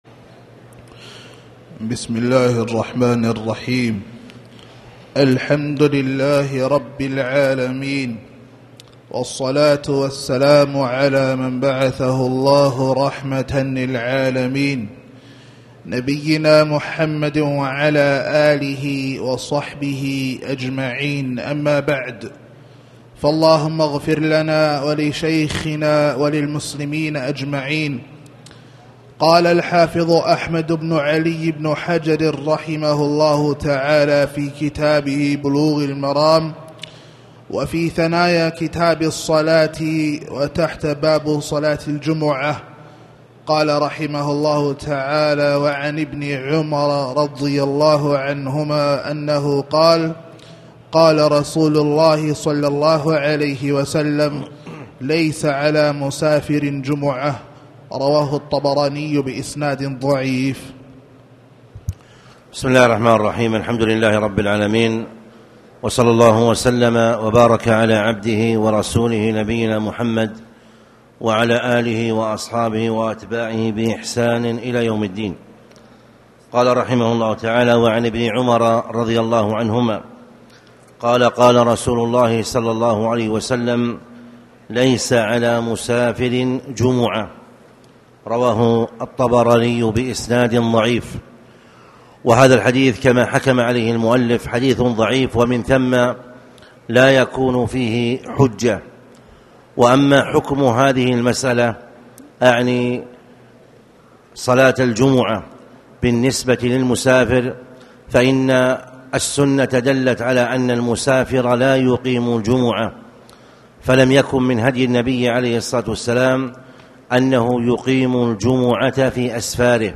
تاريخ النشر ٢٧ ربيع الأول ١٤٣٩ هـ المكان: المسجد الحرام الشيخ